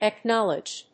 音節ac・knówl・edged 発音記号・読み方
/æˈknɑlɪdʒd(米国英語), æˈknɑ:lɪdʒd(英国英語)/
フリガナアックナリジュド